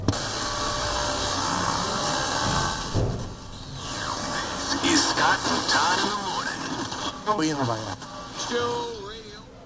无线电调谐
描述：收音机被调谐的声音。
声道立体声